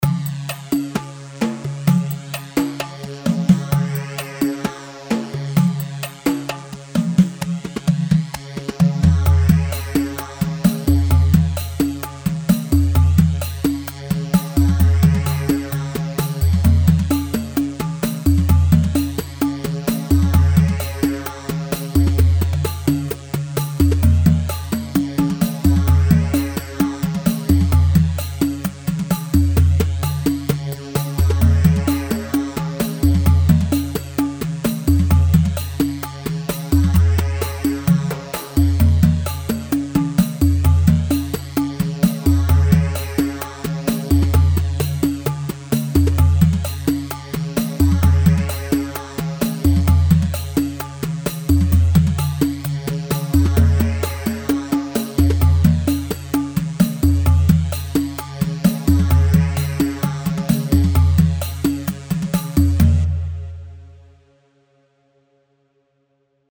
Rhumba 4/4 130 رومبا
Rhumba-4-4-130.mp3